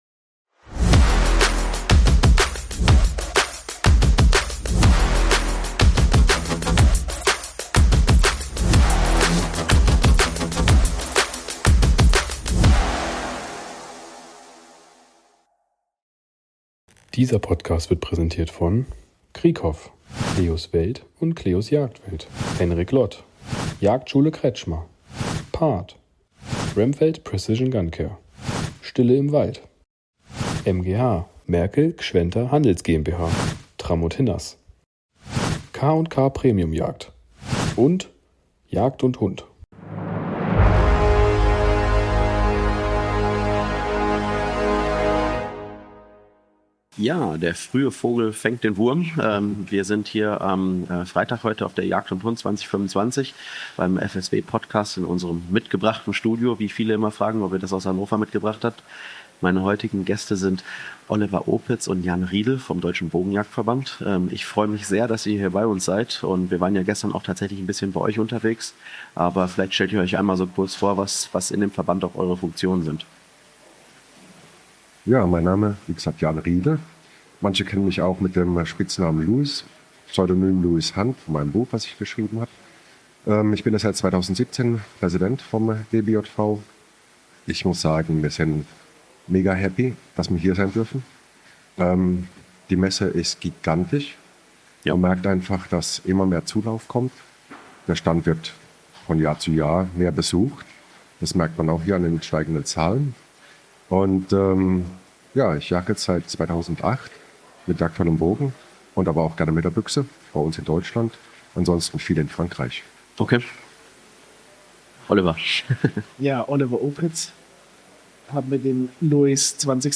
Wir sind live auf Europas größter Jagdmesse unterwegs und sprechen mit spannenden Gästen aus der Jagdszene. Ob Experten, Hersteller oder passionierte Jäger – in unseren Interviews gibt’s exklusive Einblicke, spannende Neuheiten und echte Insider-Talks rund um die Jagd. Welche Trends gibt’s 2025?